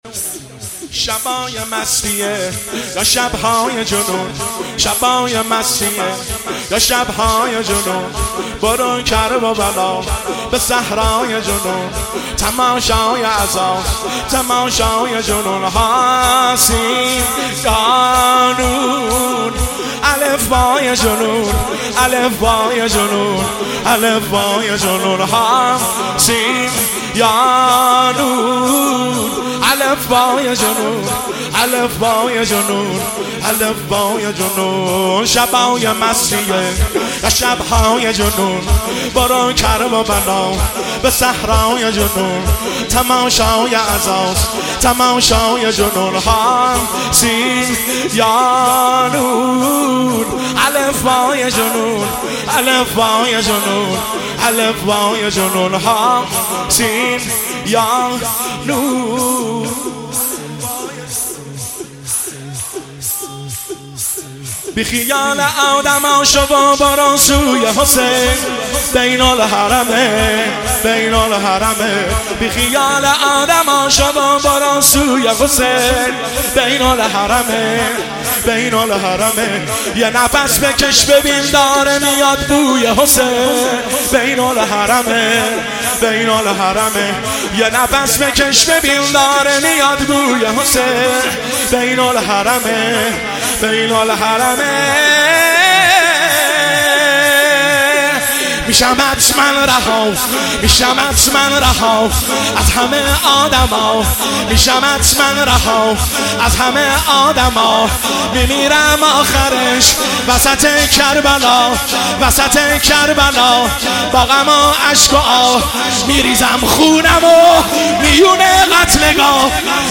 شور  شب اول محرم الحرام 1404
هیئت خادم الرضا قم